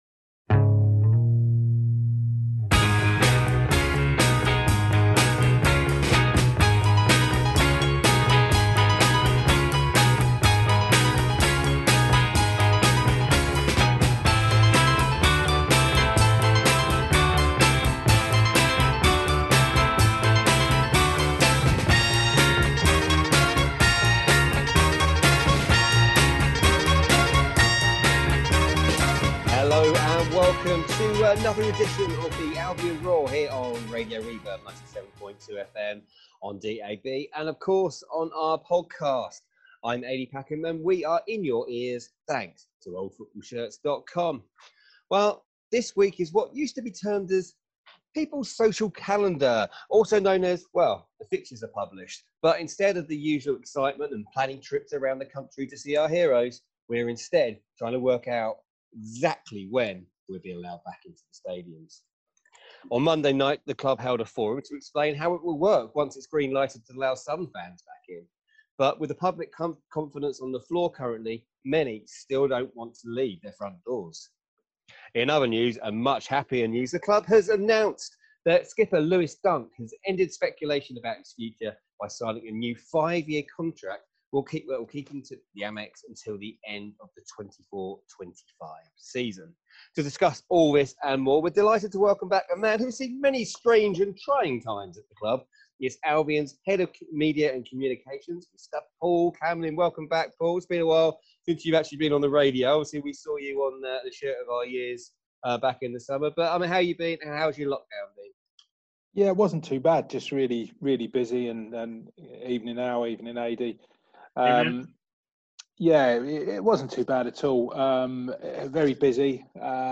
on Zoom for an hour-long natter about behind-the-scenes goings-on at the Amex during lockdown for Project Restart, and looking ahead to next season.